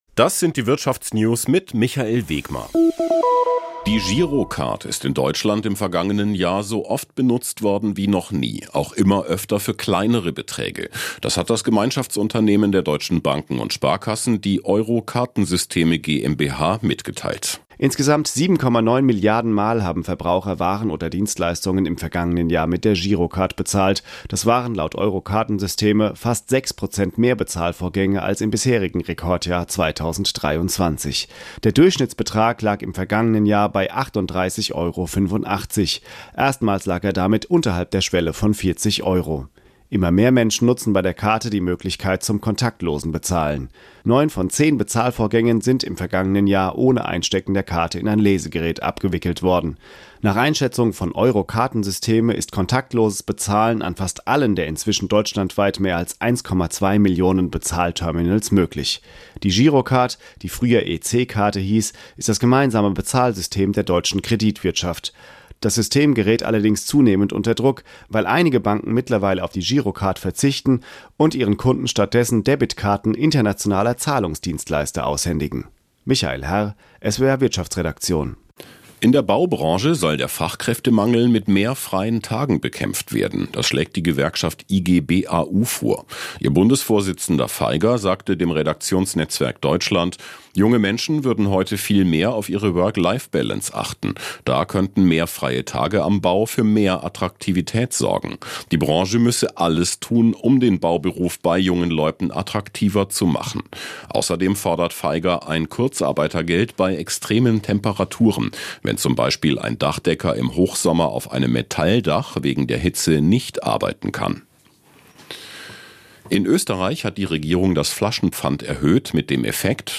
… continue reading 104 حلقات # Business News # Nachrichten # Südwestrundfunk # SWR Aktuell # Wirtschaft # Unternehmer # Börse # Beschäftigung # Arbeitnehmer # Arbeitgeber # Konzerne # Soziales # Gewerkschaft